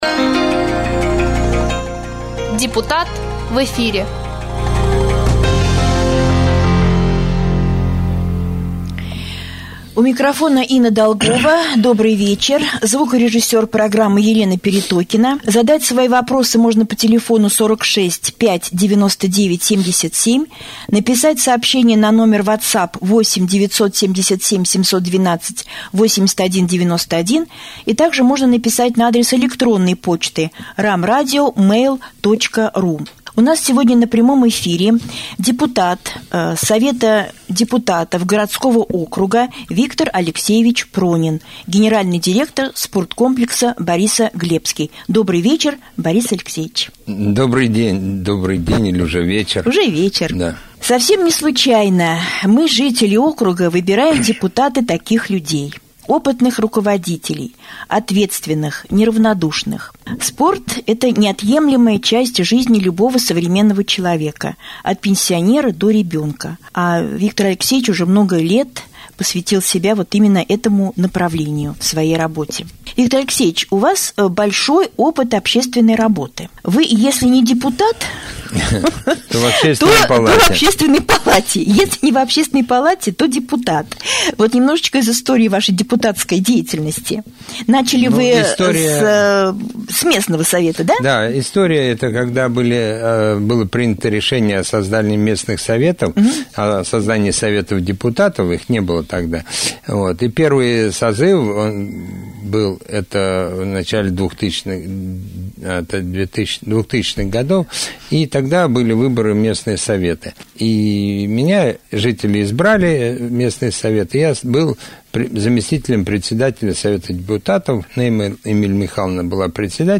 Гость студии депутат Совета депутатов Раменского г.о. Виктор Алексеевич Пронин рассказал, о своём депутатском округе, о проблемах и обращениях жителей, также о том, что уже удалось решить.